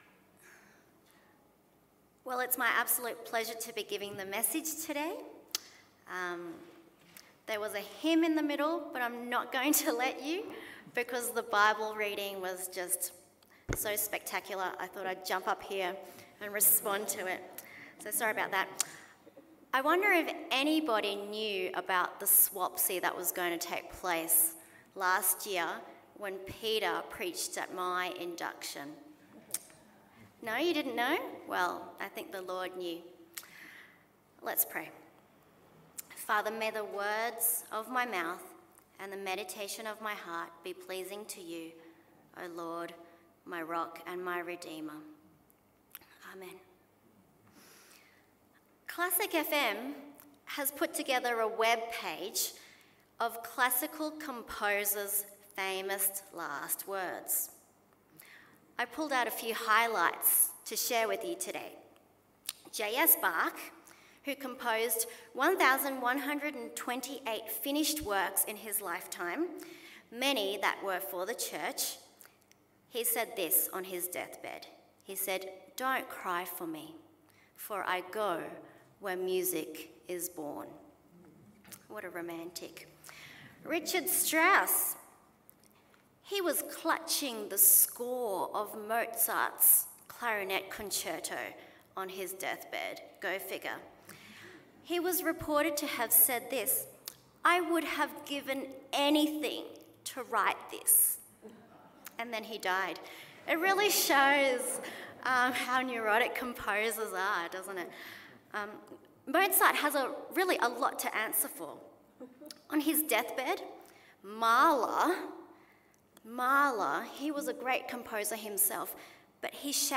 Passage: 2 Timothy 4:1-8 Service Type: AM Service Sermon